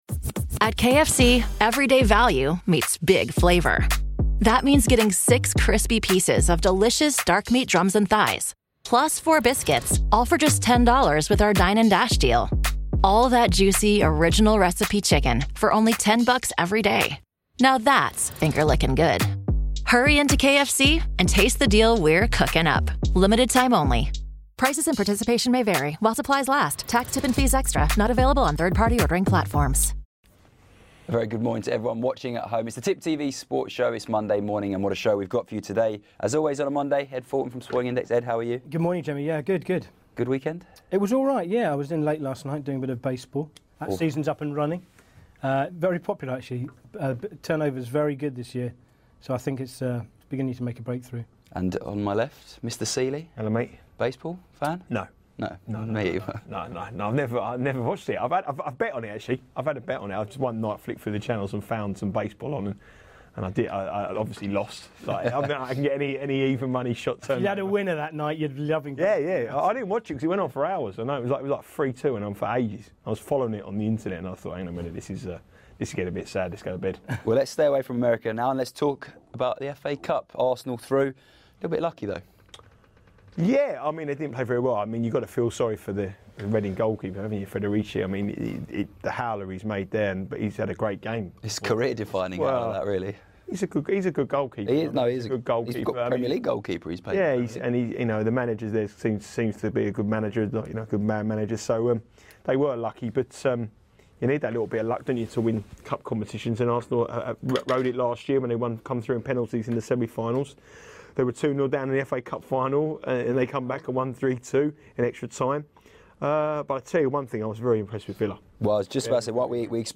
The boys in the studio have a look at all the interesting markets in the Premier League.